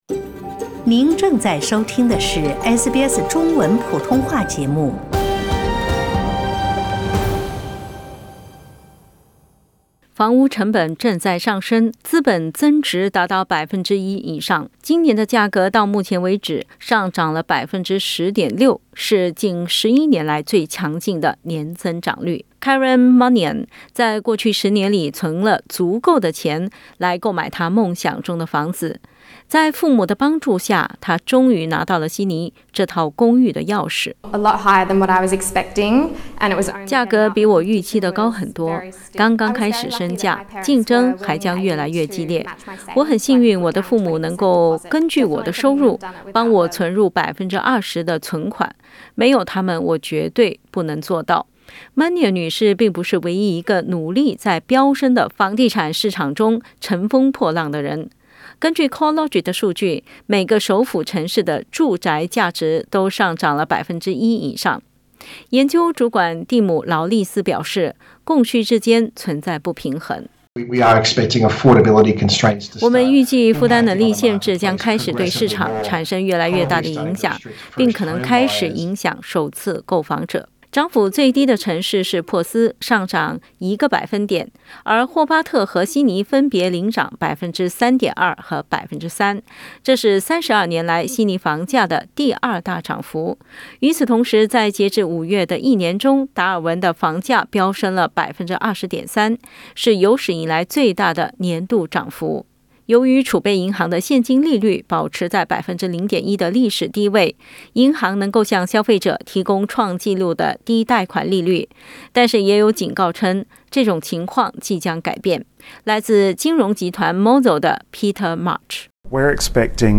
（请听报道） 澳大利亚人必须与他人保持至少1.5米的社交距离，请查看您所在州或领地的最新社交限制措施。